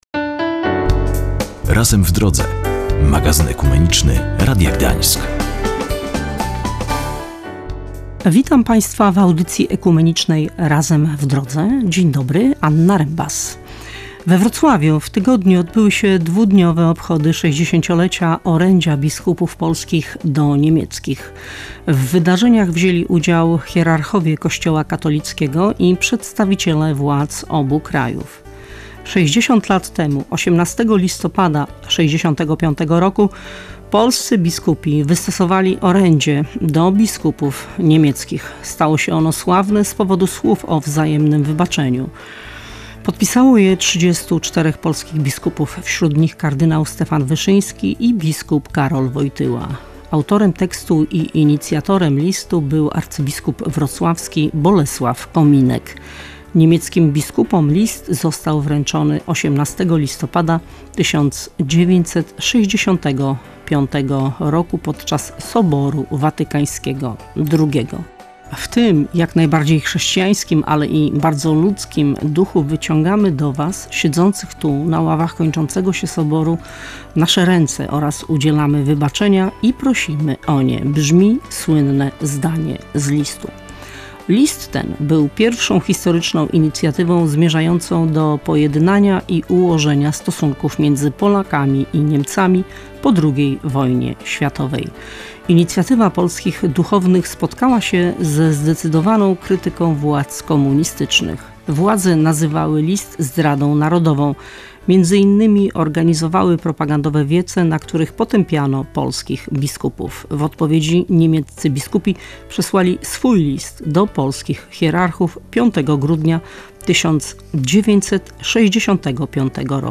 W audycji „Razem w Drodze” rozmawialiśmy o obchodach 60-lecia orędzia biskupów polskich do niemieckich. W wydarzeniach wzięli udział hierarchowie Kościoła katolickiego i przedstawiciele władz obu krajów.